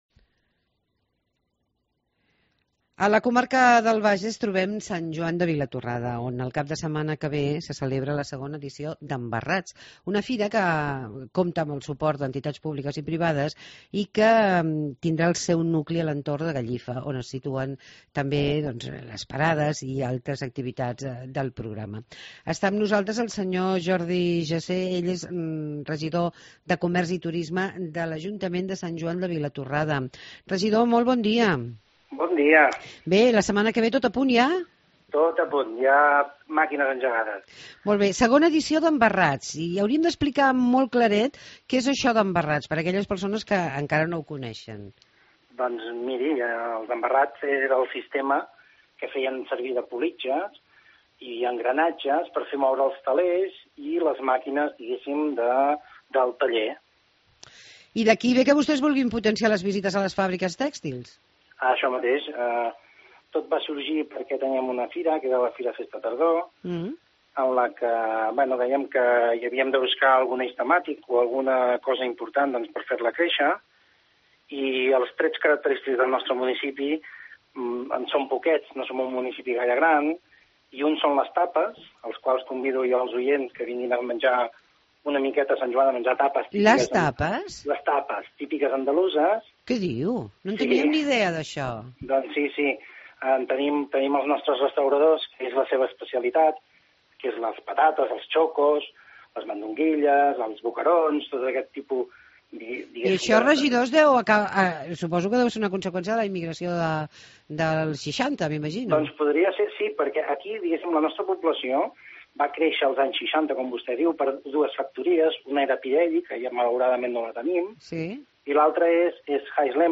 2na Edició d' EMBARRATS a Sant Joan de Vilatorrada. Parlem amb el regidor de Comerç i Turisme de l'Ajuntament: Sr. Jordi Gesè.